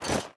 equip_diamond6.ogg